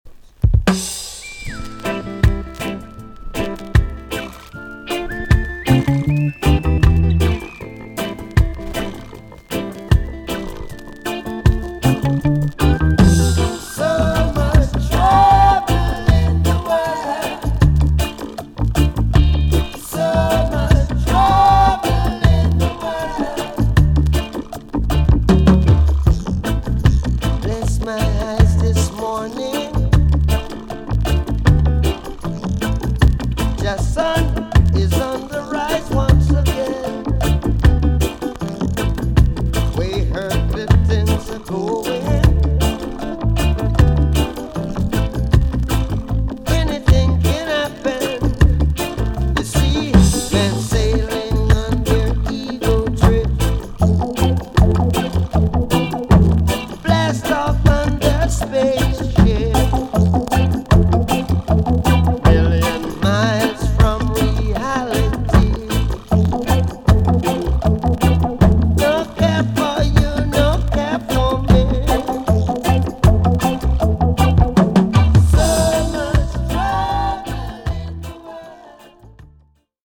B.SIDE EX-~VG+ 少しチリノイズの箇所がありますが音は良好です。